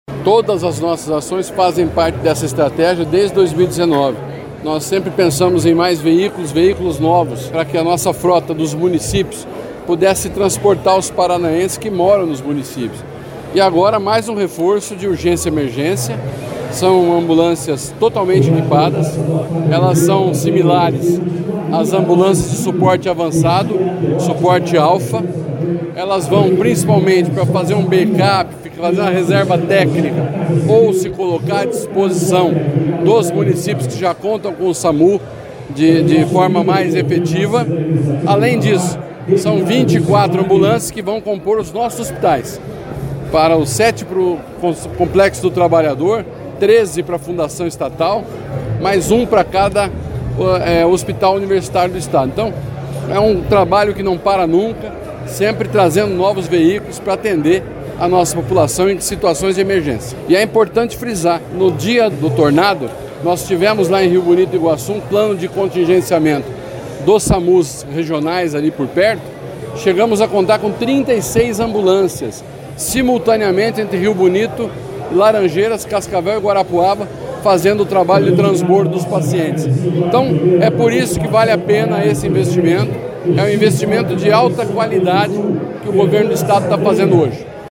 Sonora do secretário da Saúde, Beto Preto, sobre a entrega de 150 ambulâncias com UTIs para todas as regiões do Paraná